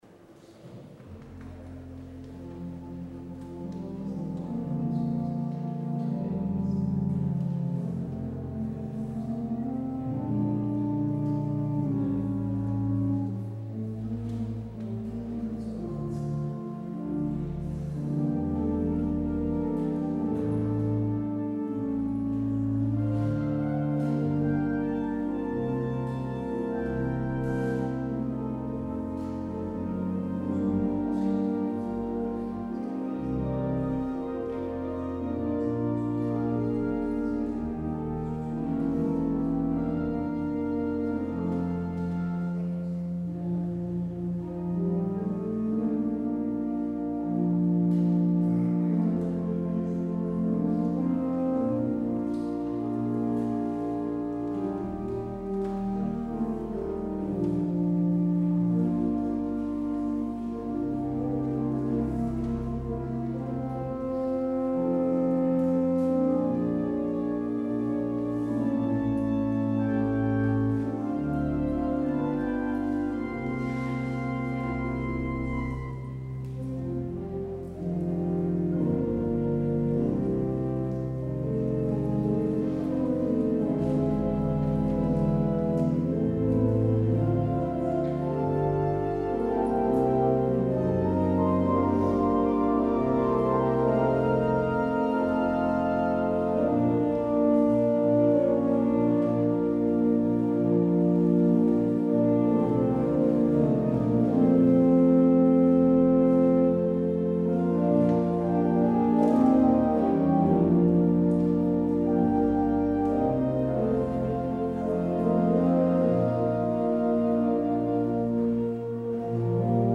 Het openingslied is: Lied 680, Kom, heilige Geest, Gij vogel Gods. Als slotlied hoort u: Lied 871, Jezus zal heersen waar de zon.